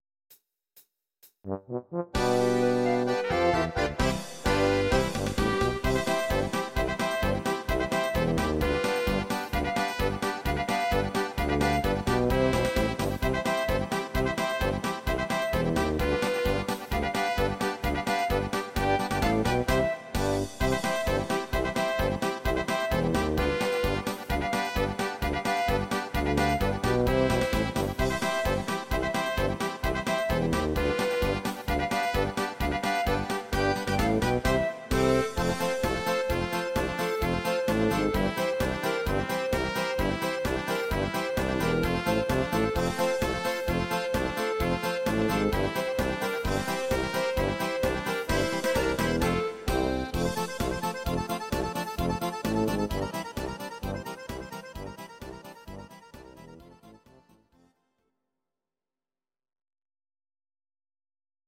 instr. Trompete